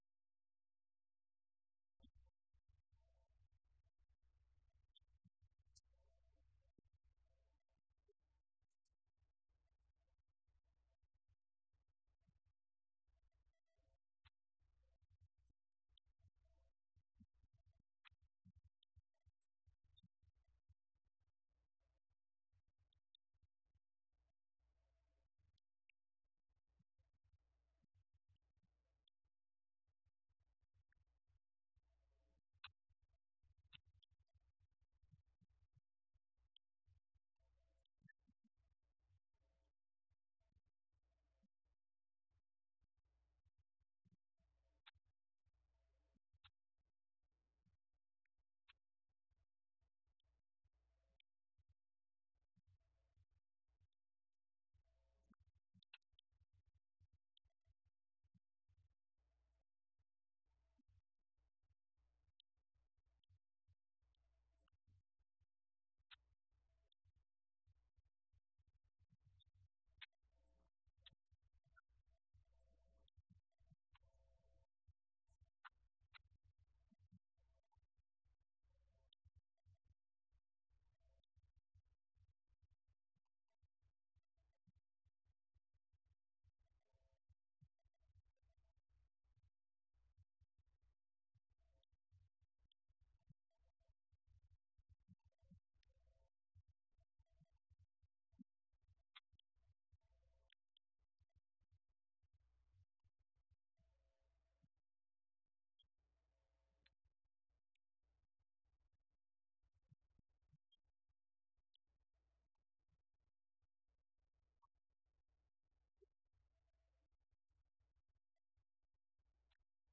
Event: 17th Annual Schertz Lectures
lecture